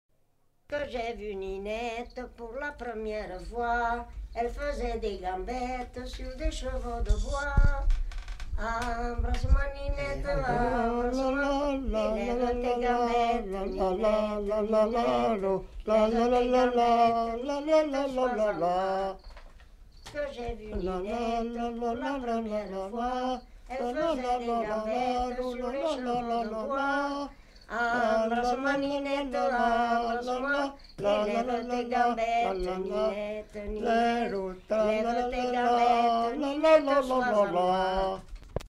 Lieu : Puylausic
Genre : chant
Effectif : 2
Type de voix : voix de femme ; voix d'homme
Production du son : chanté ; fredonné